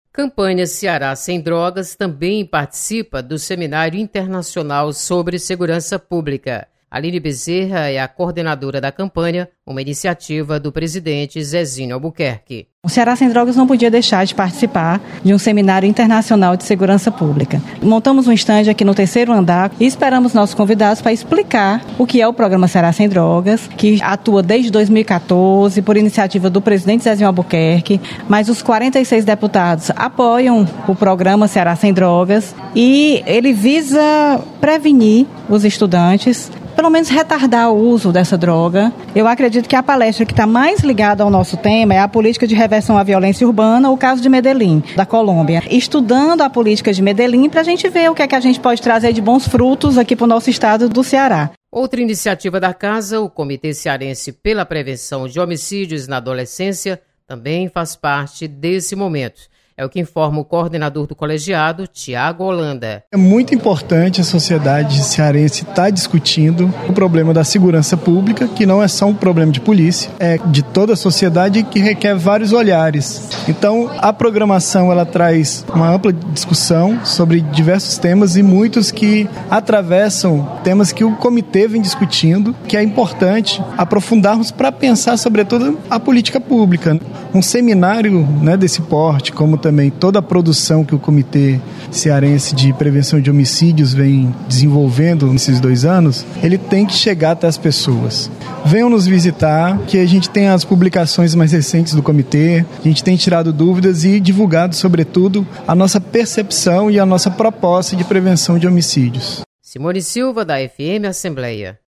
Campanha Ceará Sem Drogas é destaque no Seminário Internacional Sobre Segurança Pública.  Repórter